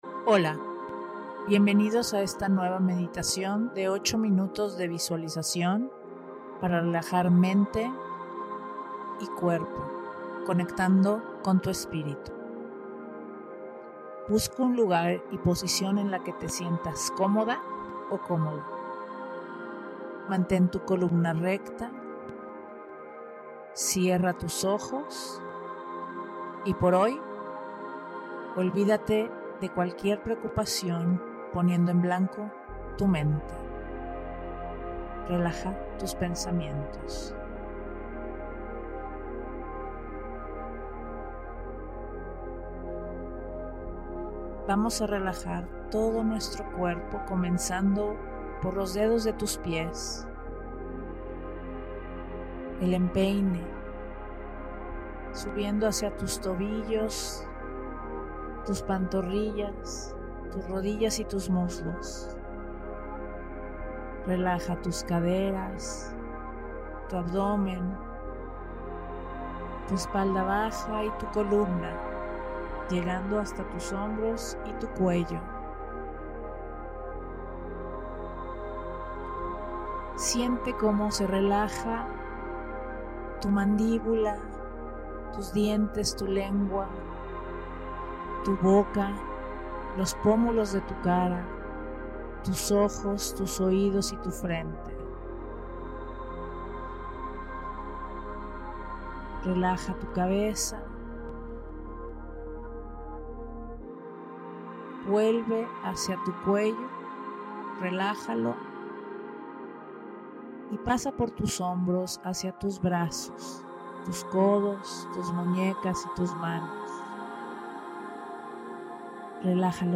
Meditación Guiada 8 minutos para sound effects free download